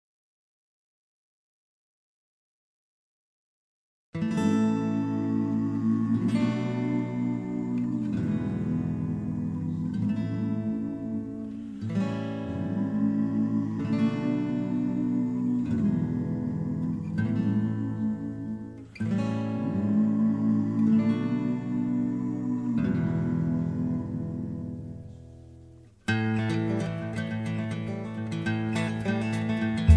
backing tracks
country rock, country music